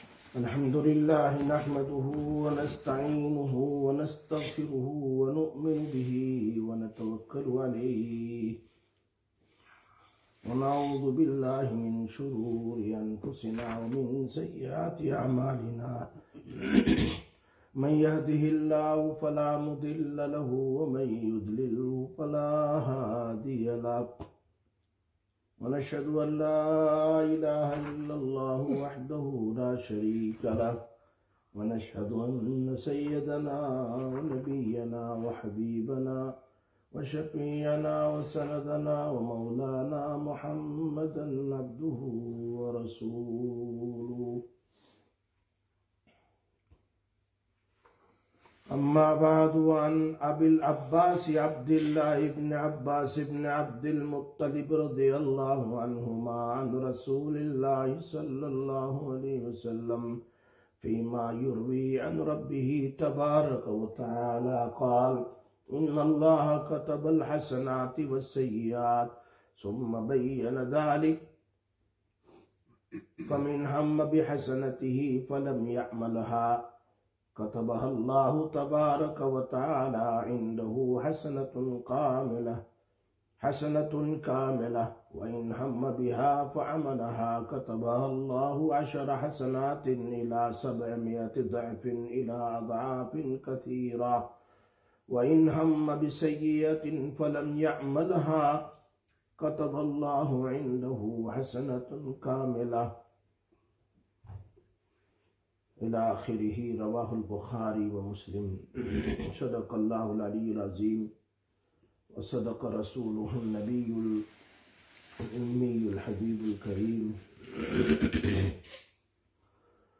07/01/2026 Sisters Bayan, Masjid Quba